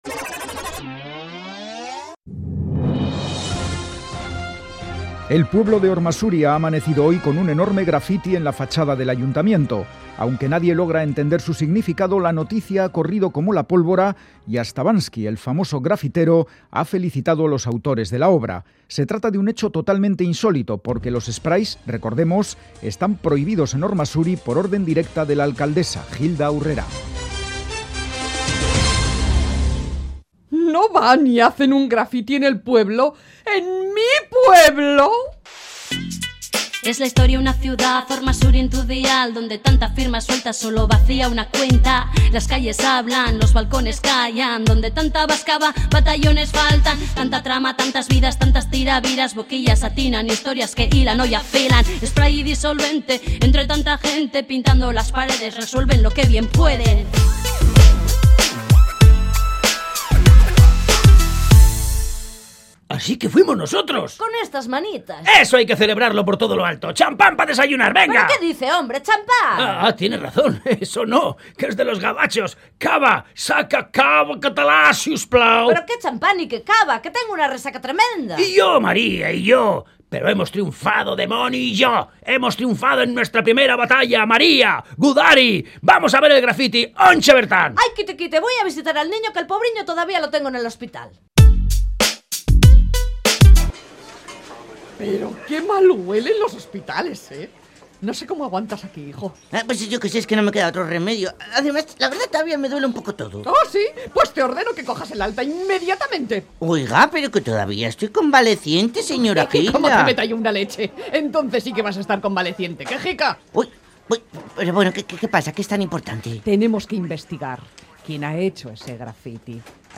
Entrega número 18 de la Radio-Ficción “Spray & Disolvente”